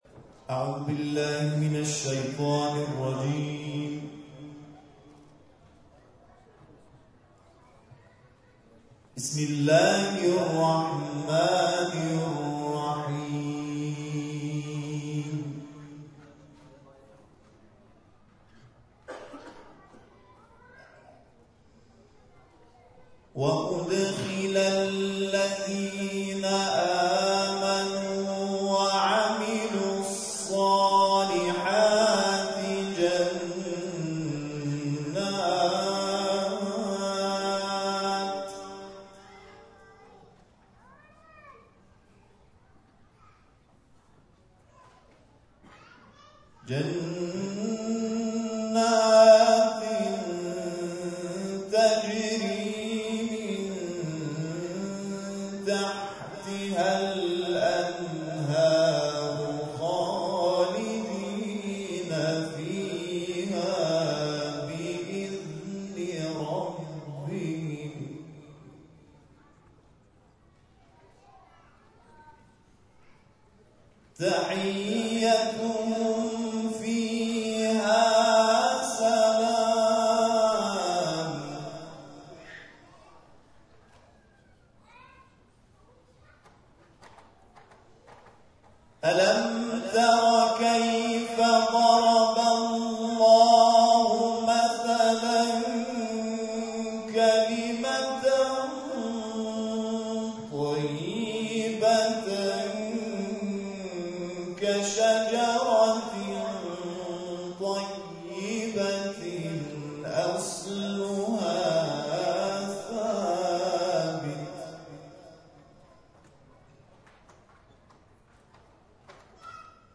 گاهی رهین با قرآن، مدیون قرآن می‌شود+صوت و عکس